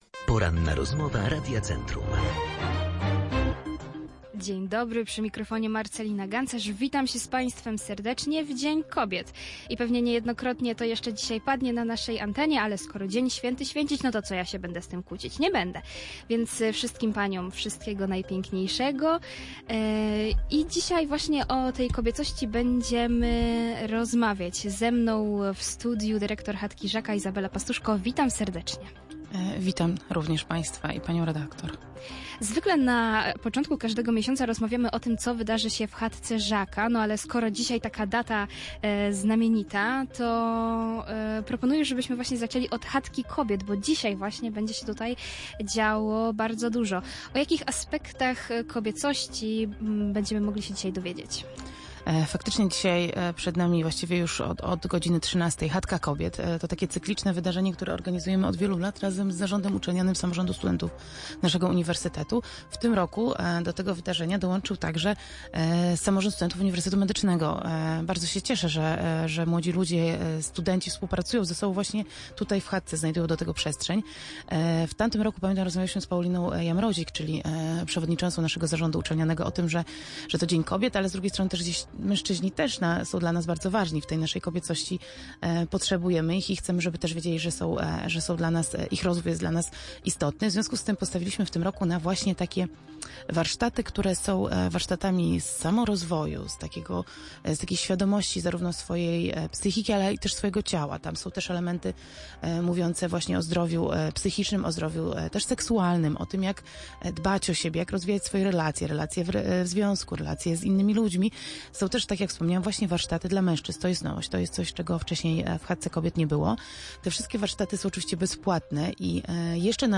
Co wydarzy się w Chatce Żaka mówiliśmy podczas Porannej Rozmowy Radia Centrum.